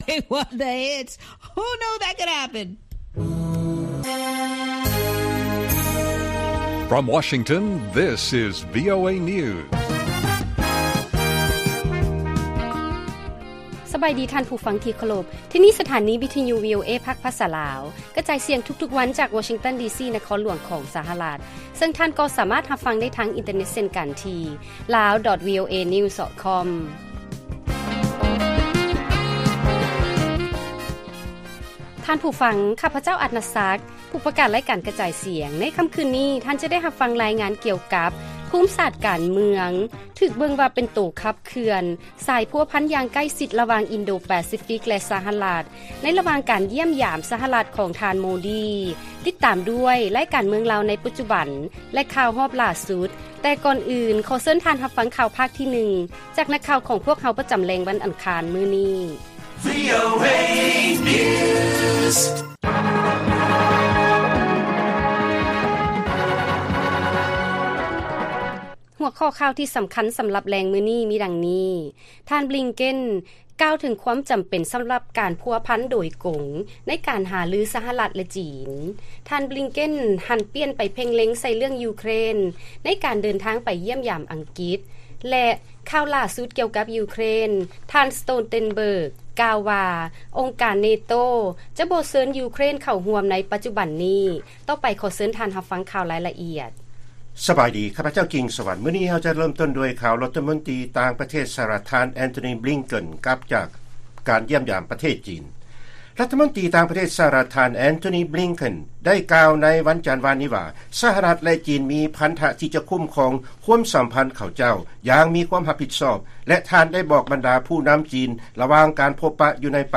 ລາຍການກະຈາຍສຽງຂອງວີໂອເອ ລາວ: ທ່ານບລິງເຄັນ ກ່າວເຖິງຄວາມຈຳເປັນ ສຳລັບການພົວພັນໂດຍກົງ ໃນການຫາລື ສະຫະລັດ ຈີນ